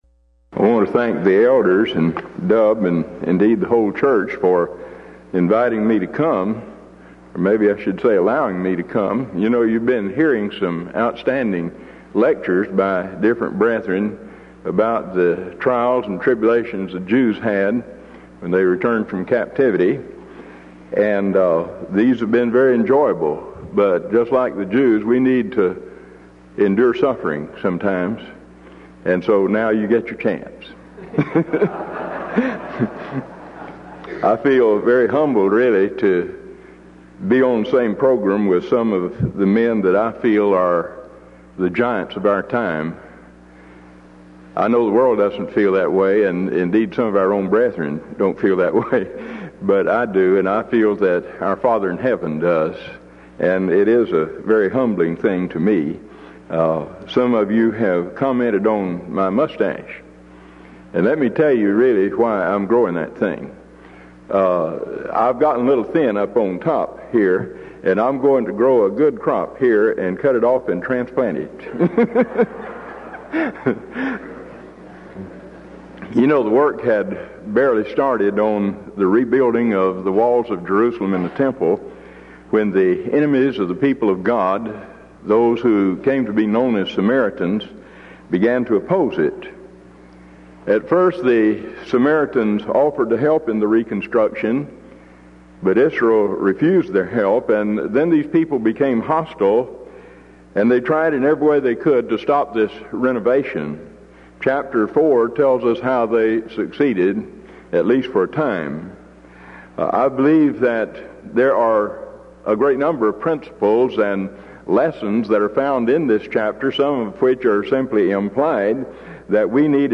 Event: 1992 Denton Lectures
lecture